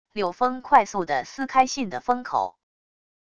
柳风快速的撕开信的封口wav音频